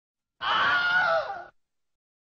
A Shout